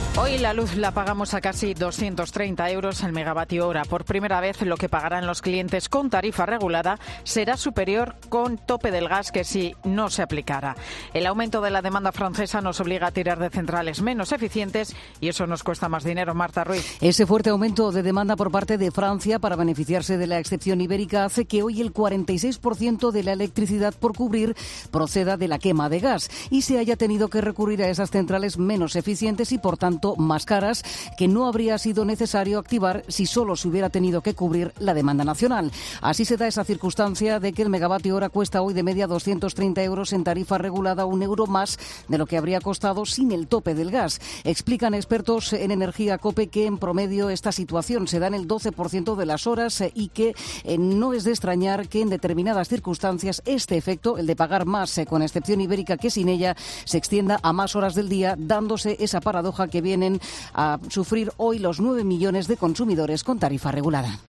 Este lunes la luz es más cara por la demanda de Francia. Crónica